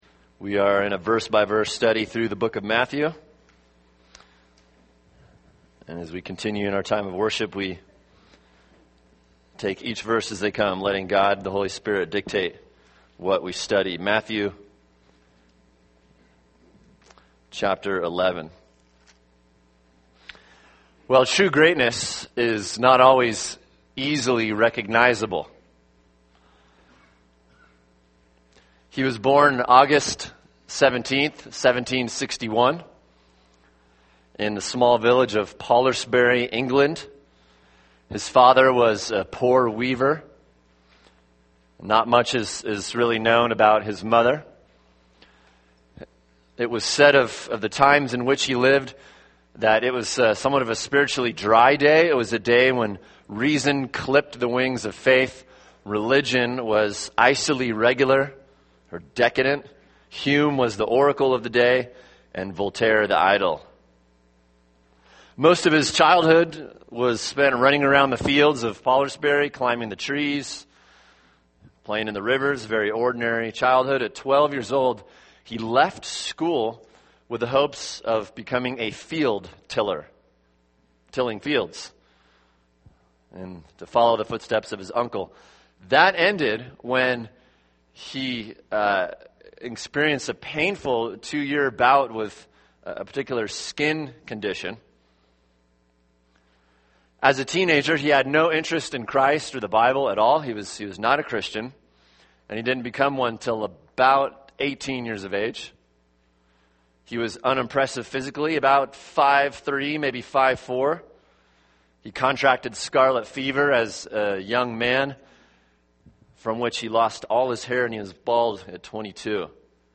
[sermon] Matthew 11:7-11 – God’s Kind of Greatness | Cornerstone Church - Jackson Hole